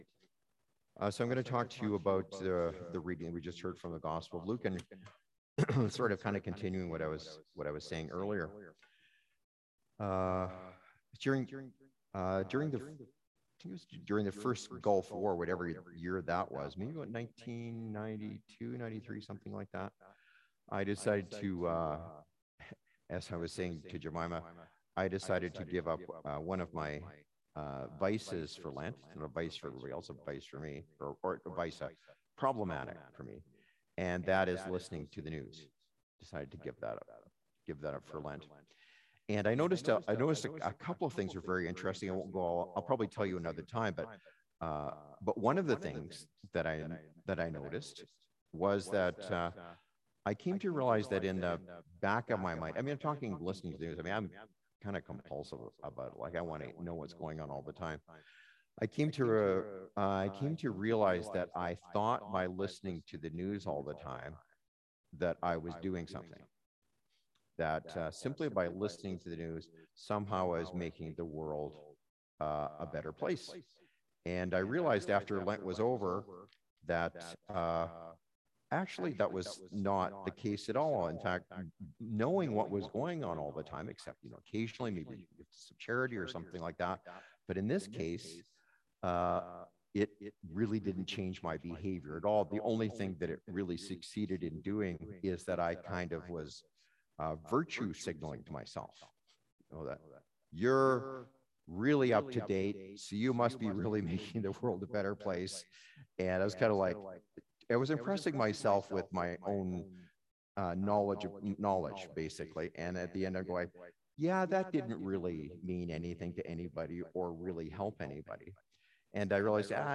Sermons | St. George's Anglican Church
Our apologies, due to technical issues the sound quality is not the best.